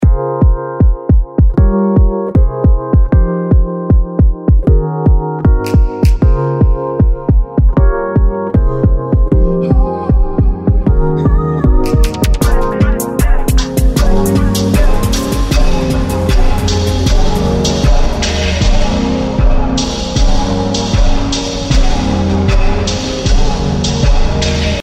カテゴリーのSPACEは、空間系のプリセットを格納していますが、その中から「Wide & Weird」をご紹介。
幻想的なスロウ効果を得られ、楽曲の最後の展開などに全体がけしたりもできるかと思い、試してみました。
親切にBACK SPINも入れてくれていますので、そういった展開もありですね。